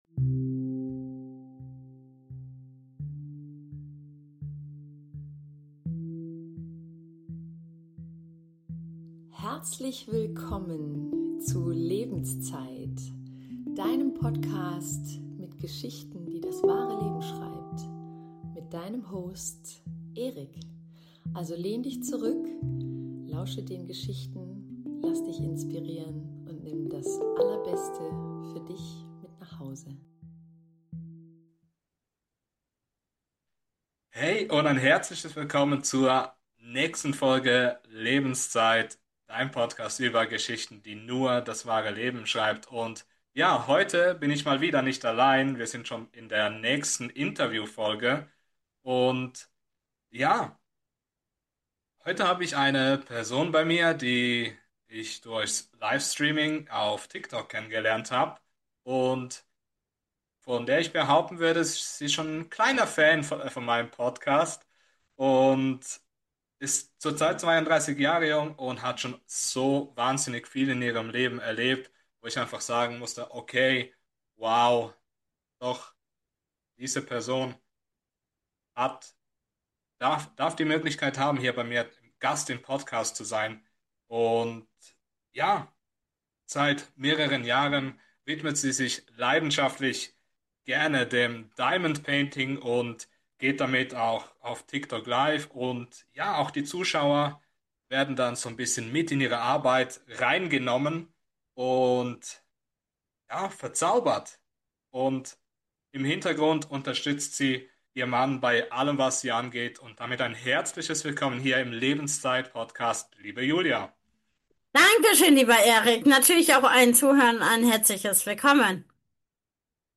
Hey und Herzlich Willkommen zur nächsten Folge in der ich auch heute nicht alleine bin wir starten direkt rein in die nächste Interview Folge.